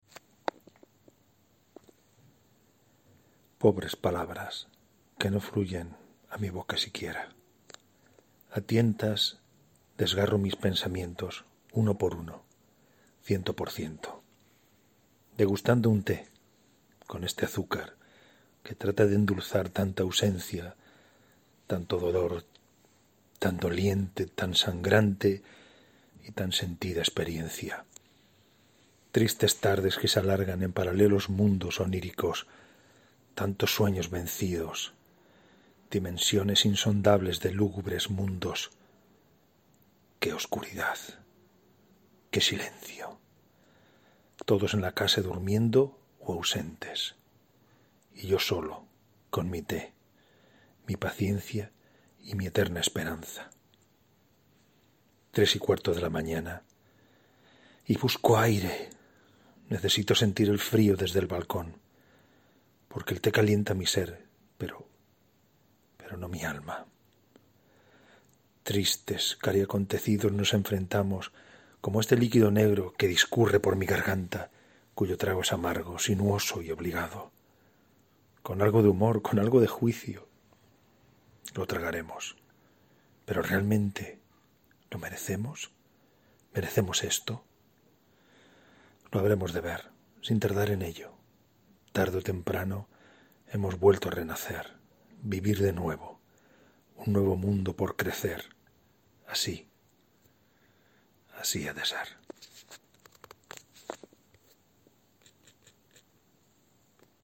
Sonrisa Poética: «El té del amanecer» – recitado.